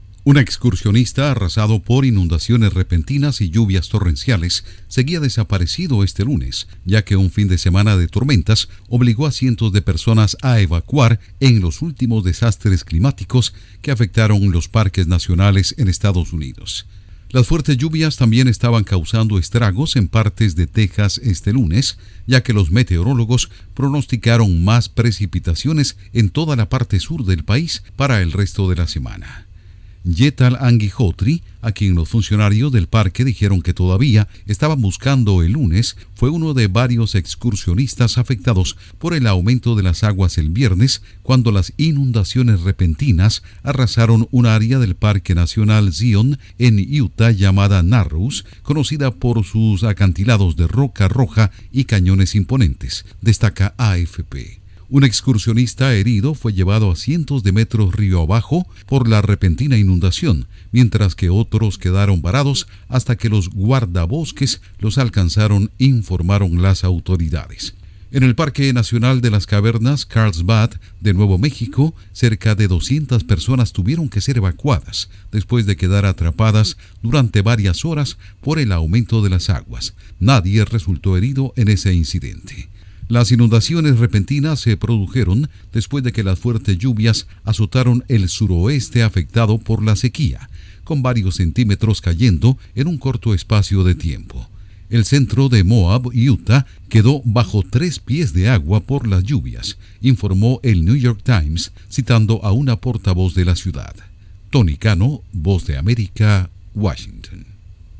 Inundaciones repentinas golpean parques de EE.UU. y estados del sur en los últimos desastres climáticos. Informa desde la Voz de América en Washington